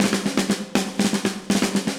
AM_MiliSnareC_120-03.wav